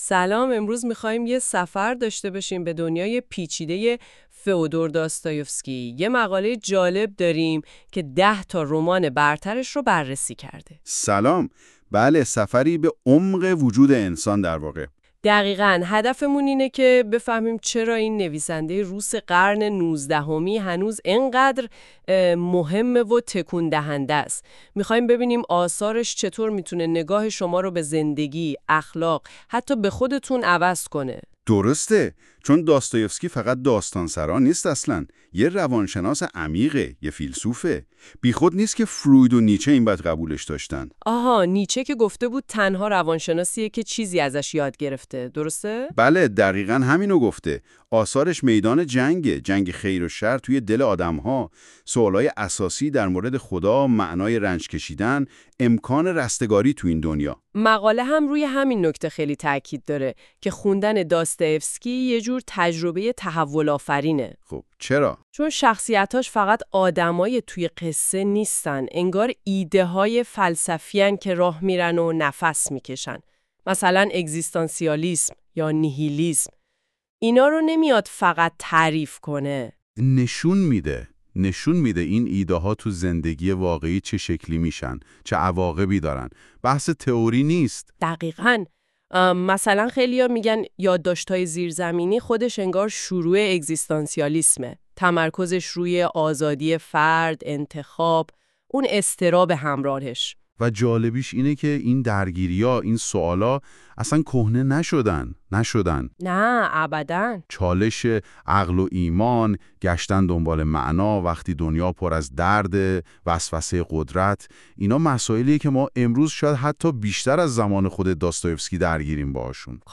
🎧 خلاصه صوتی 10 تا از بهترین رمان های داستایوفسکی که نباید از دست بدهید
این خلاصه صوتی به صورت پادکست و توسط هوش مصنوعی تولید شده است.